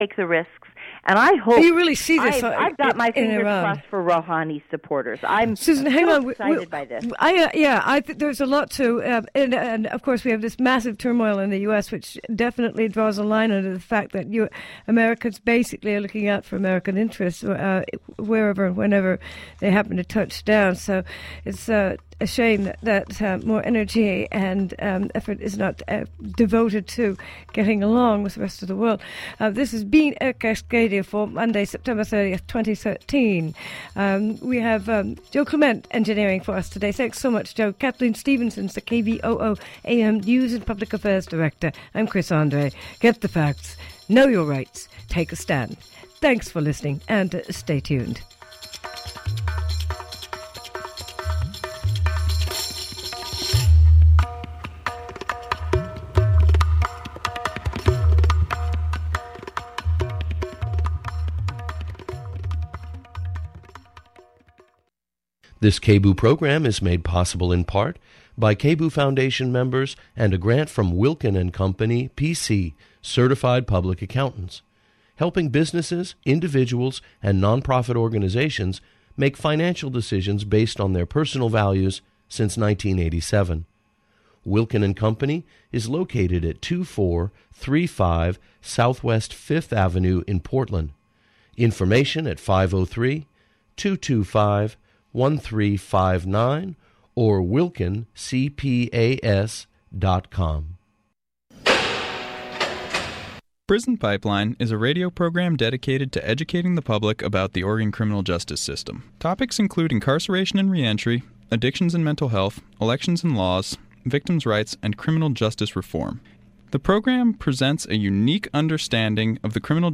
THE KINGDOM OF RARITIES - A Conversation with author